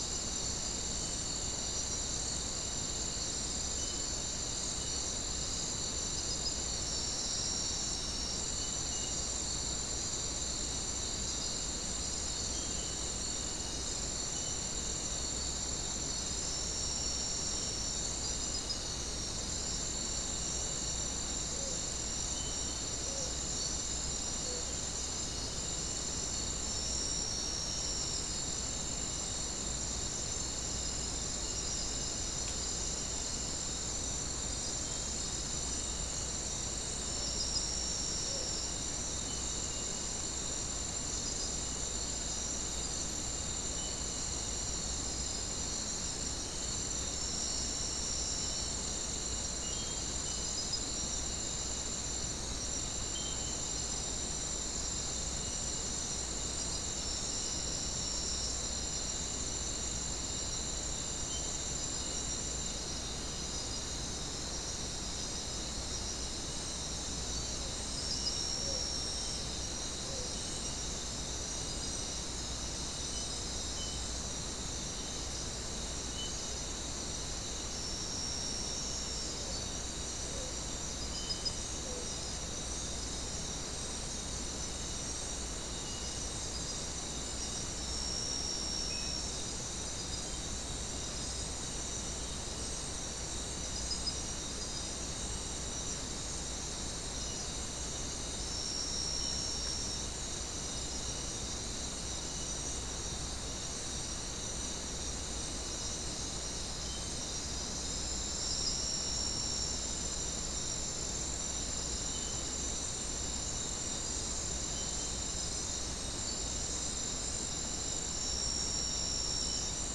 Non-specimen recording: Soundscape Recording Location: South America: Guyana: Mill Site: 1
Recorder: SM3